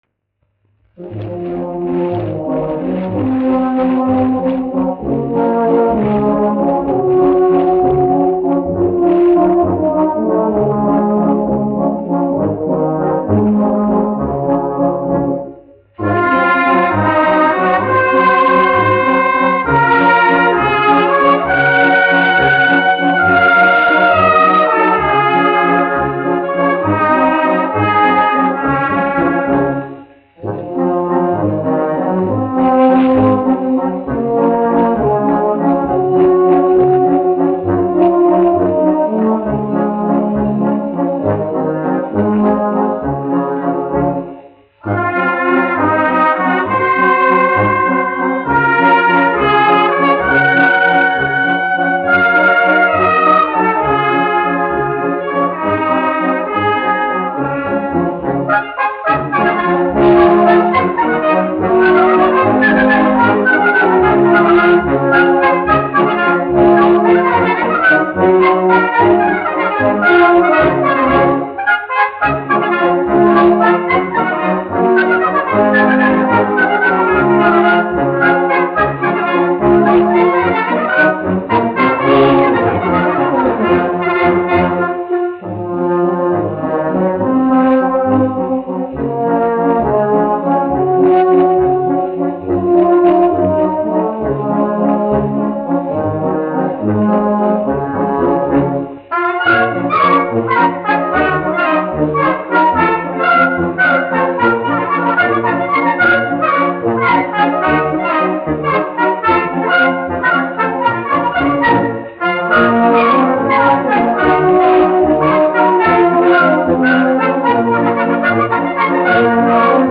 1 skpl. : analogs, 78 apgr/min, mono ; 25 cm
Pūtēju orķestra mūzika
Skaņuplate